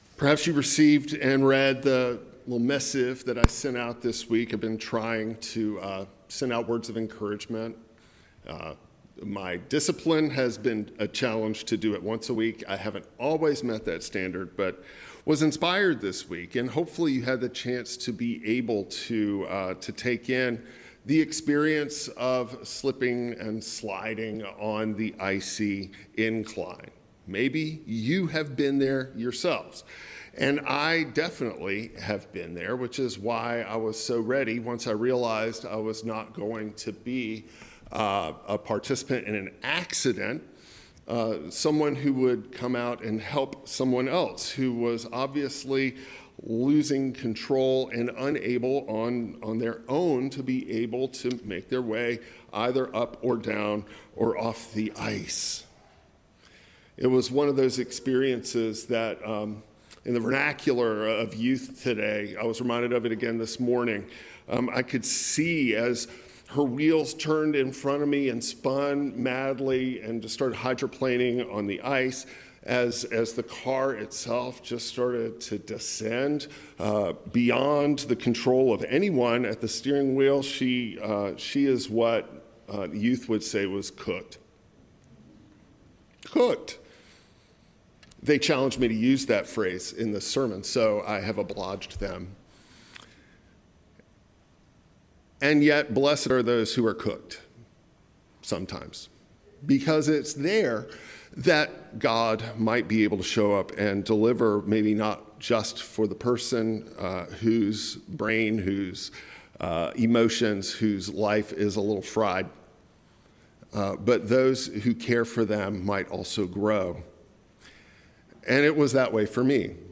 A sermon on Isaiah 58, 1 Corinthians 2, and Matthew 5 exploring communal repair, burnout, shared ministry, and Christ who enters the breach.